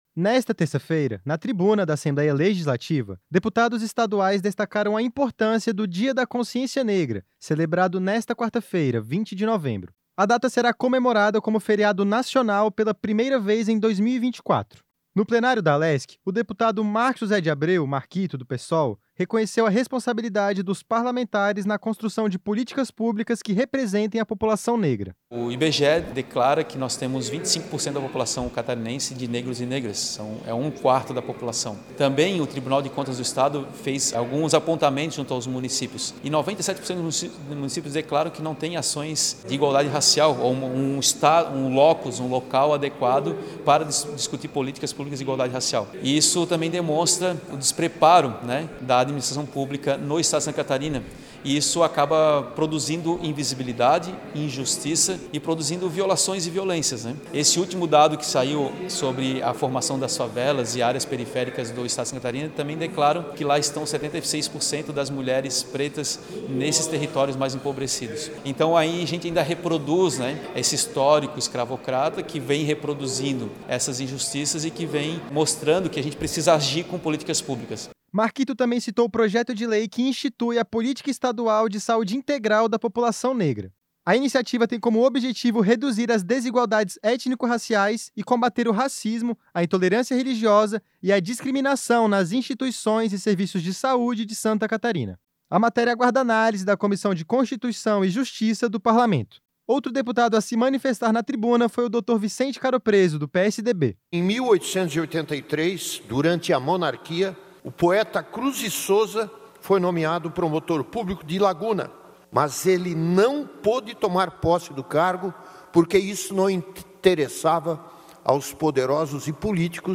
Nesta terça-feira (19), na tribuna da Assembleia Legislativa, deputados estaduais destacaram a importância do Dia da Consciência Negra, celebrado nesta quarta-feira, 20 de novembro.
Entrevistas com:
- deputado Marcos José de Abreu - Marquito (PSOL);
- deputado Dr. Vicente Caropreso (PSDB).